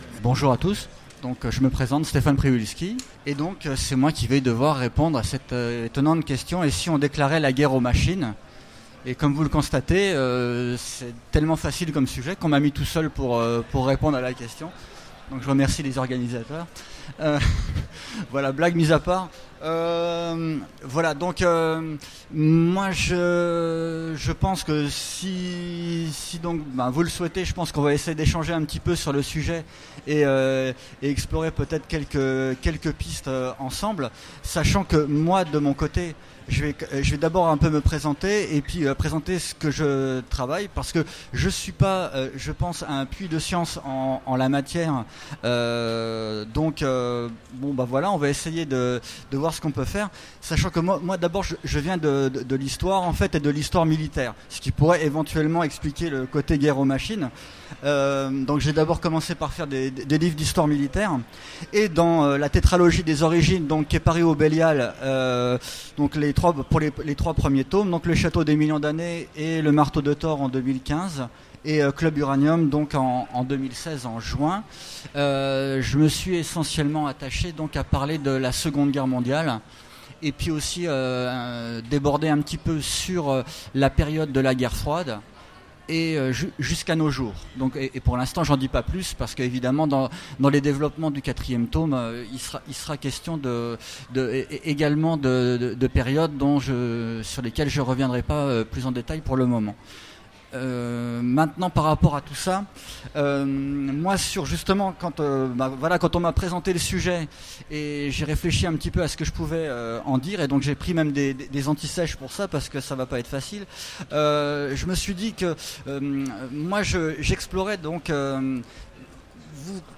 Utopiales 2016 : Expérience de pensée : et si on déclarait la guerre aux machines ?
Mots-clés Guerre Machine Conférence Partager cet article